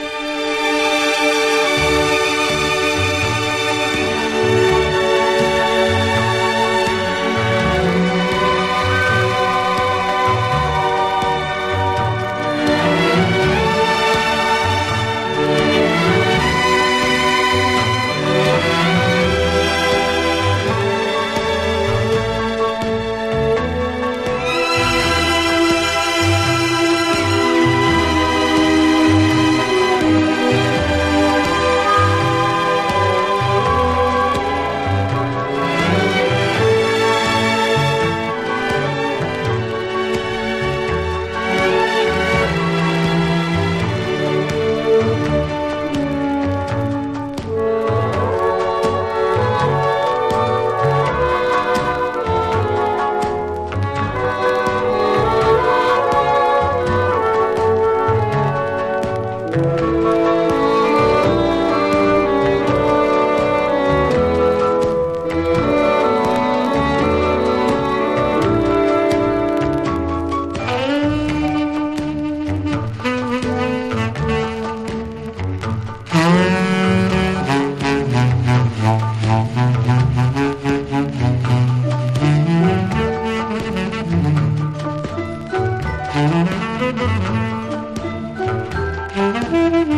とてつもなく優美でロマンティックな魅惑のストリングス・ラウンジ！